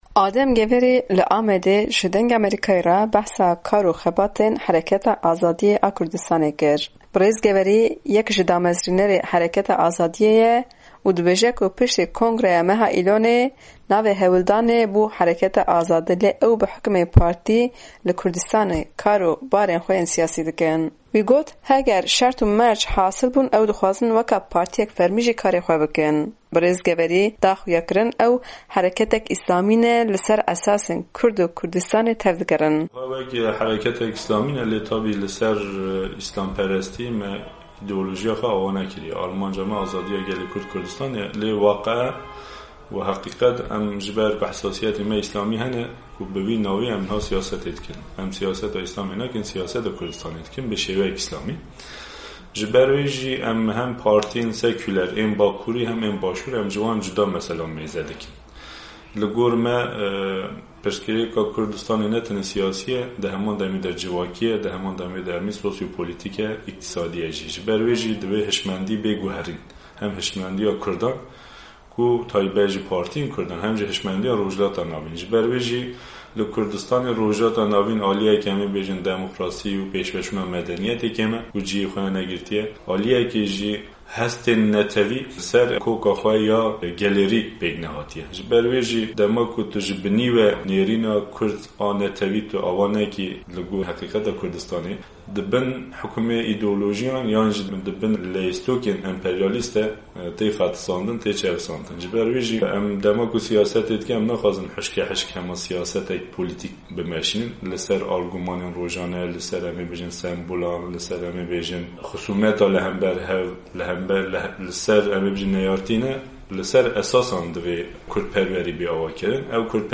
Dirêjahiya hevpeyvînê di fayla deng de ye.